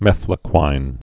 (mĕflə-kwīn, -kwēn)